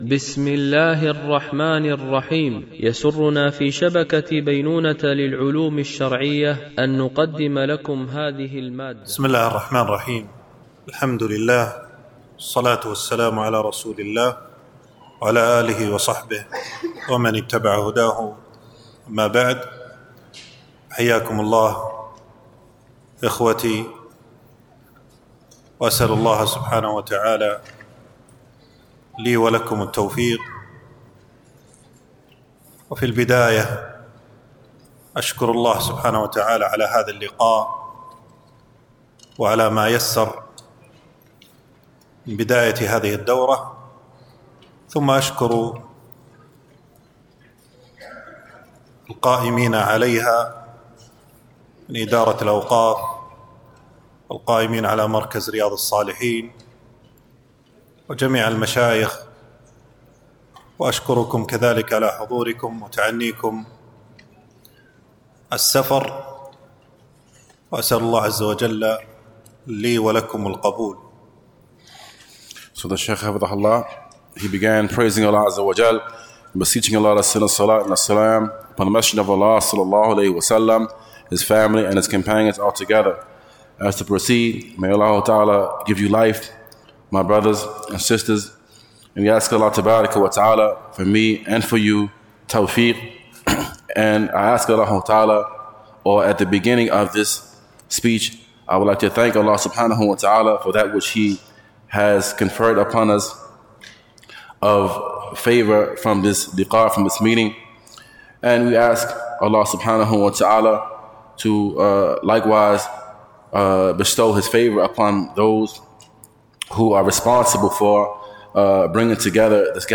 دورة علمية مترجمة للغة الإنجليزية، لمجموعة من المشايخ، بمسجد أم المؤمنين عائشة رضي الله عنها